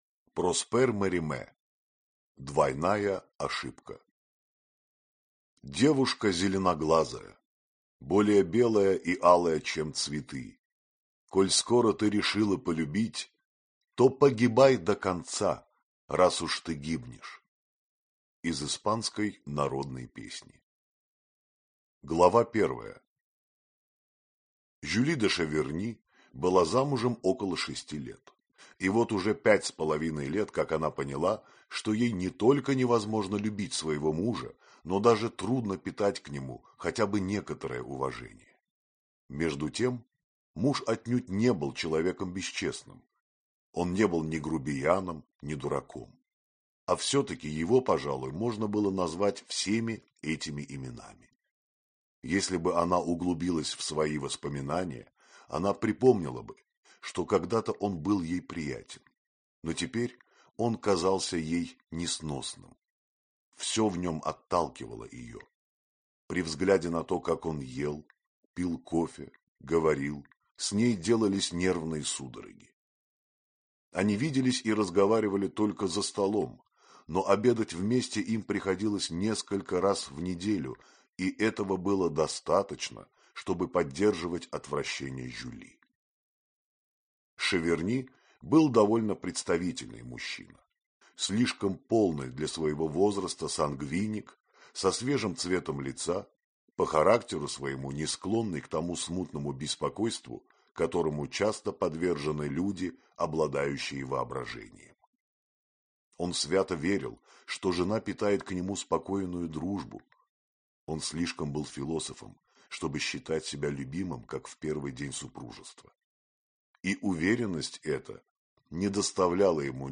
Аудиокнига Кармен. Коломба. Двойная ошибка | Библиотека аудиокниг
Прослушать и бесплатно скачать фрагмент аудиокниги